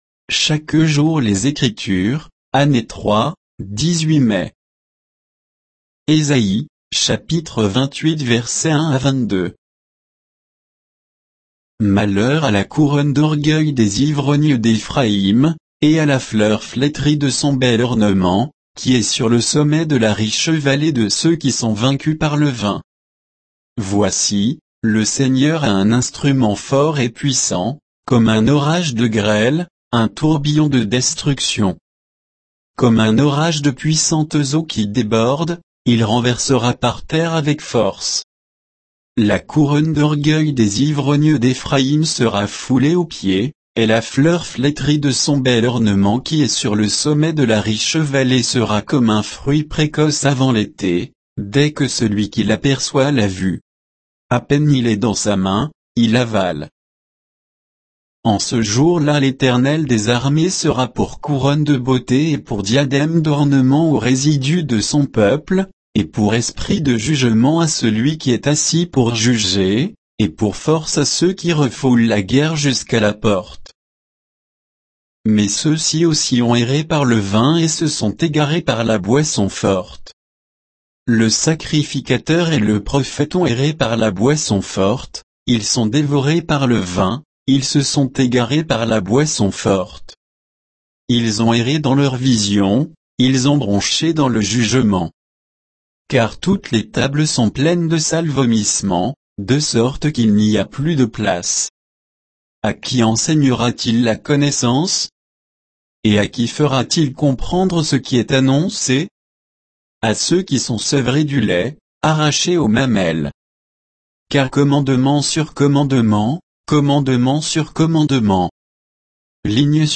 Méditation quoditienne de Chaque jour les Écritures sur Ésaïe 28